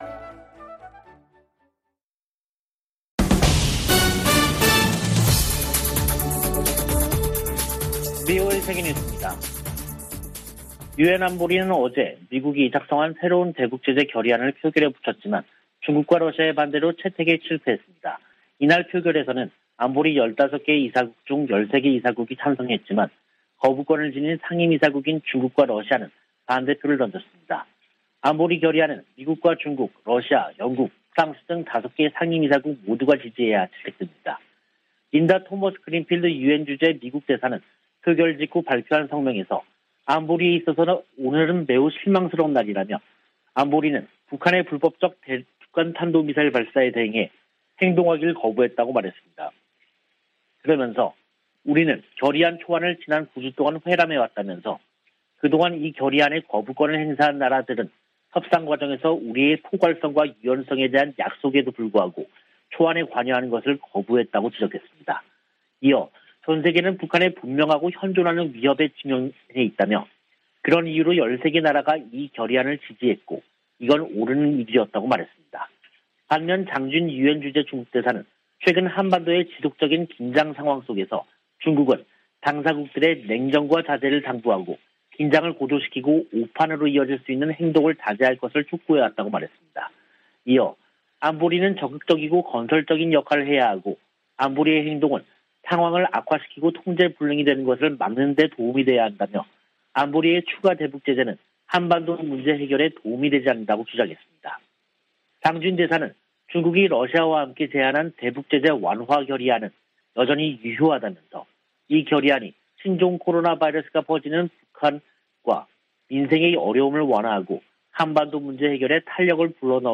VOA 한국어 간판 뉴스 프로그램 '뉴스 투데이', 2022년 5월 27일 3부 방송입니다. 유엔 안보리가 중국과 러시아의 반대로 새 대북 결의안 채택에 실패했습니다. 토니 블링컨 미 국무장관은 대중국 전략을 공개하면서 북한 핵 문제를 상호 ‘협력 분야’로 꼽았습니다. 미 국무부가 올해 초 제재한 북한 국적자 등의 이름을 연방관보에 게시했습니다.